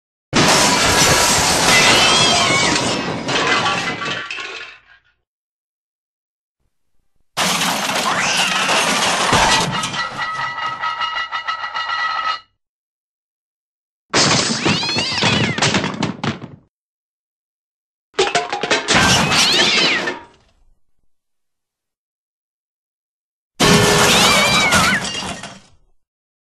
Cartoon Crashes with Cat Noises SFX
Cartoon Crashes with Cat Noises SFX Description This is the cartoon crashes with cat noises sound effects.
Cartoon_Crashes_with_Cat_Noises_SFX.mp3